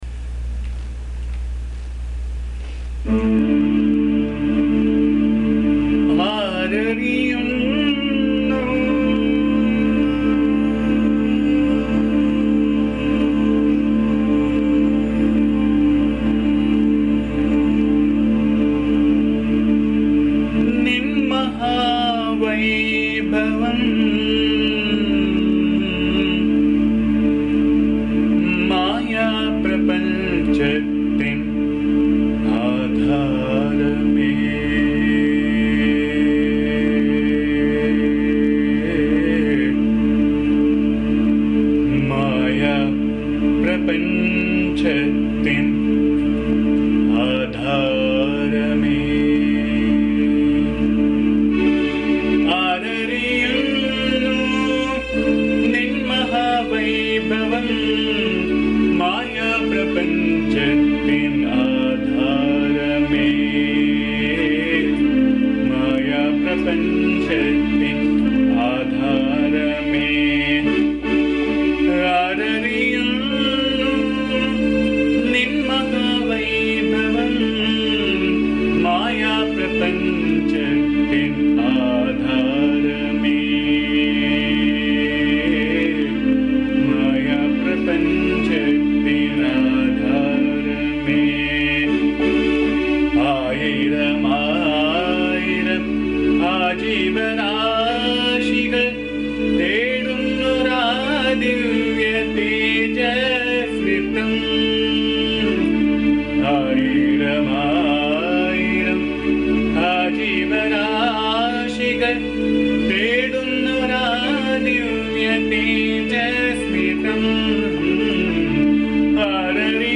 bhajan song